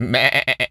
goat_baa_calm_01.wav